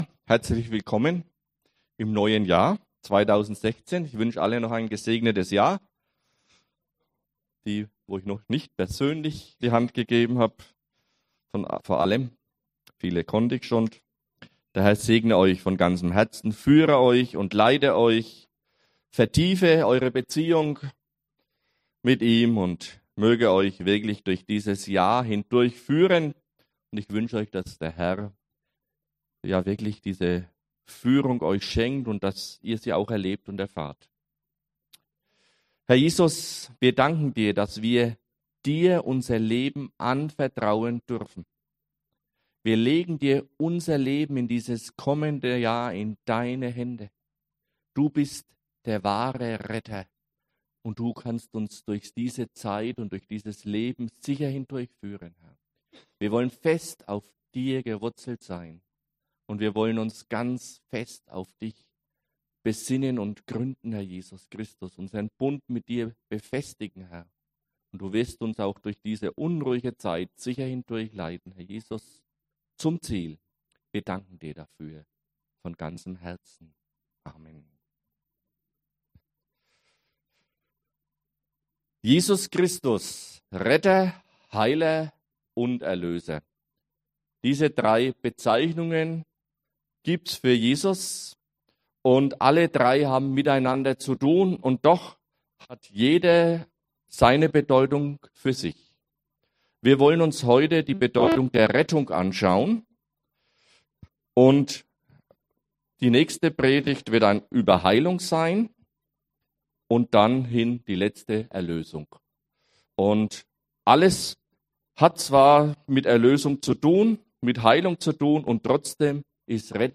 Wie hat dir diese Predigt gefallen?